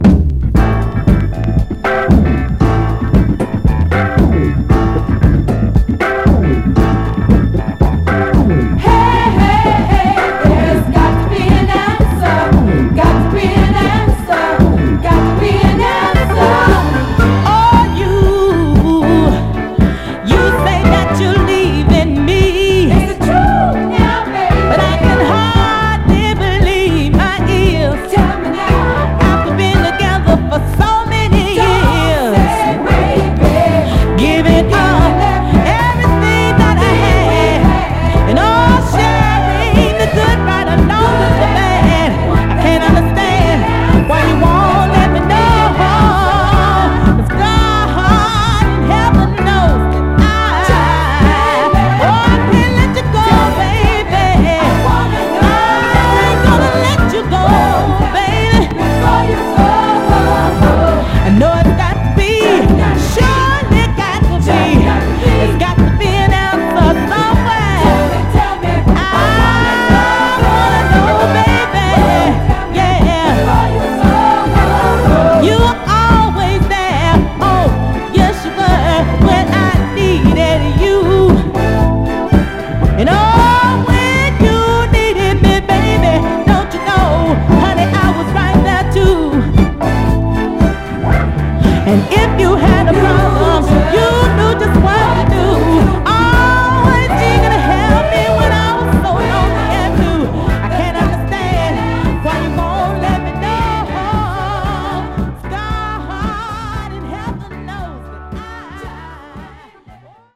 ノース・キャロライナ発のガールズ・グループ
バブルガムな瑞々しいヴォーカルで聴かせる、キャッチーでクロスオーヴァーなシカゴ・ソウルの人気タイトルです！
イントロ等でチリつく箇所ありますが、目立つノイズは少なくDJプレイは問題ないかと。
※試聴音源は実際にお送りする商品から録音したものです※